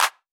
JJ-AY-Clap1Dry.wav